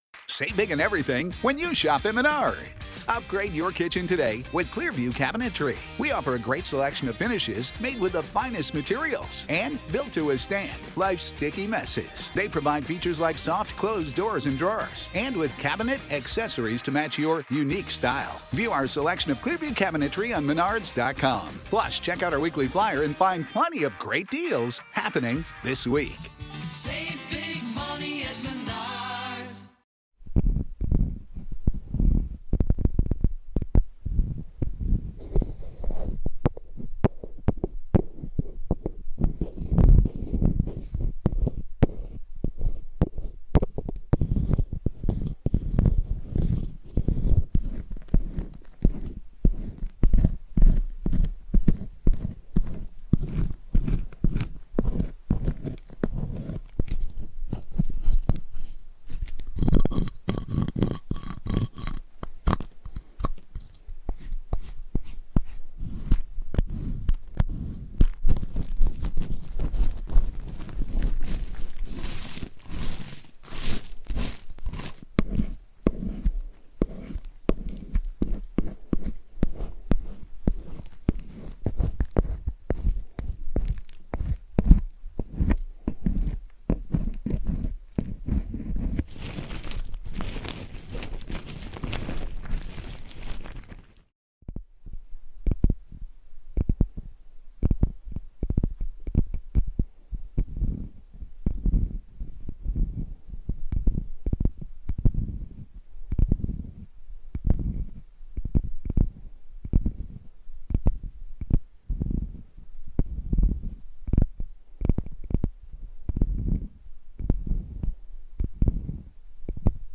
Satisfying Soap Carving for Ultimate ASMR Bliss
Every time you press play on Deep Sleep Sounds – Rain, White Noise, ASMR & Meditation for Insomnia, you’ll notice that all ads are placed at the very beginning so your listening experience stays calm, uninterrupted, and deeply relaxing.